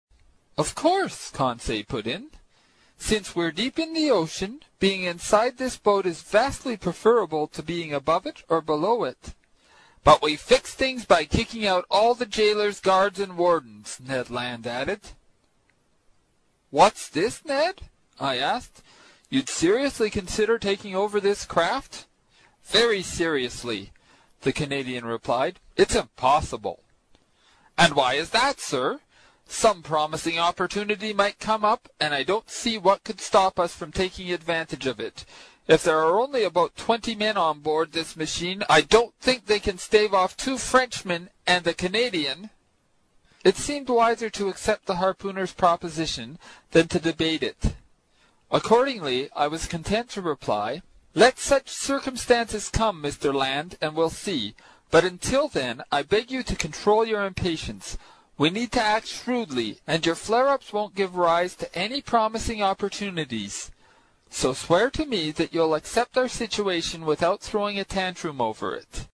在线英语听力室英语听书《海底两万里》第125期 第9章 尼德兰的愤怒(10)的听力文件下载,《海底两万里》中英双语有声读物附MP3下载